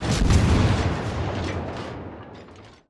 WULA_Basttleship_Shootingsound_M.wav